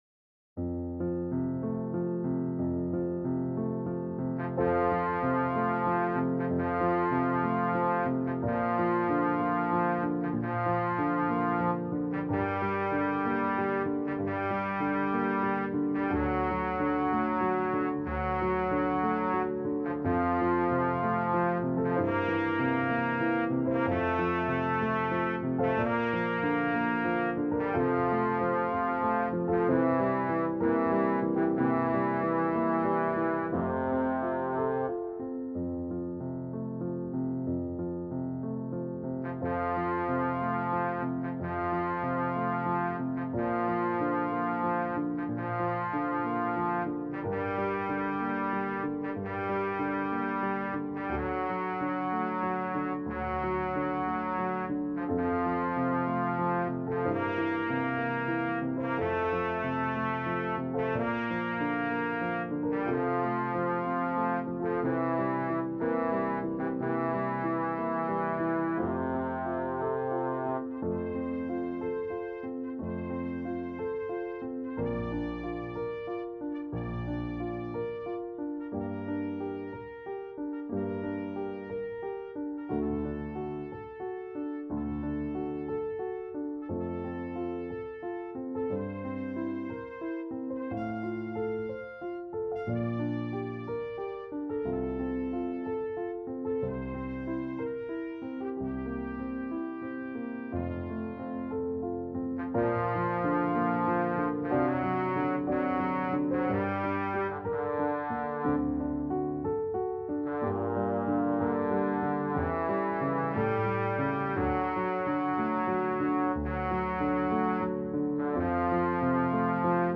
장로성가단 연습음원